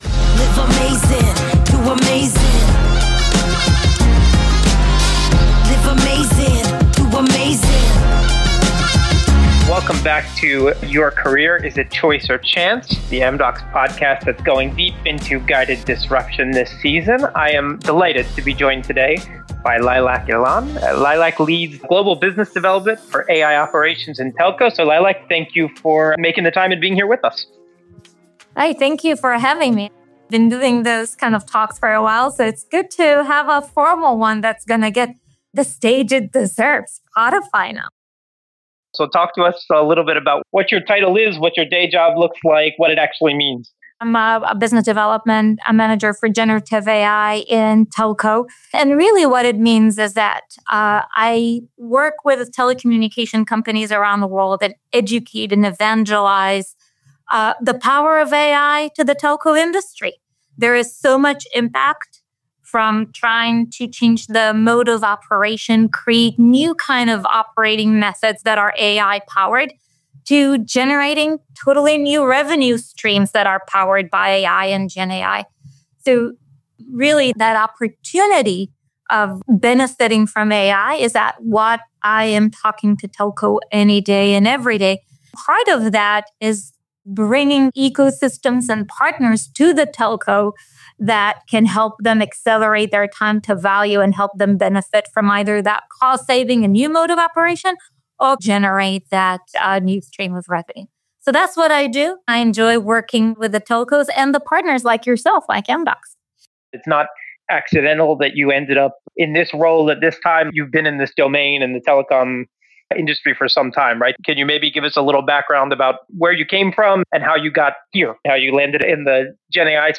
In our second season, we have a unique addition: Alex, a new AI co-host. His innovative voice is here to challenge the status quo and bring a one-of-a-kind AI approach to every episode.